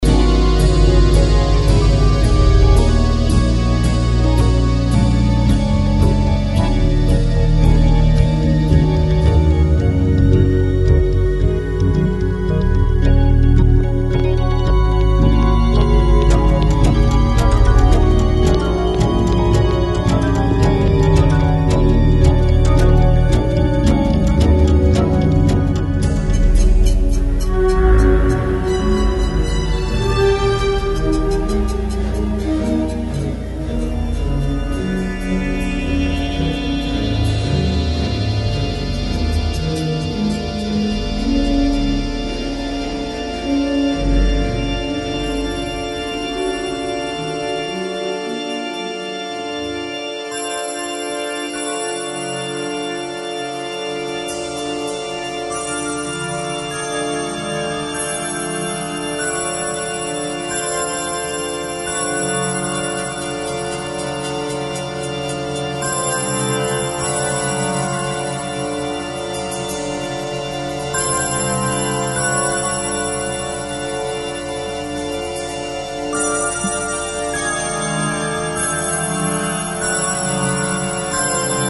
As an electronic symphonic music creation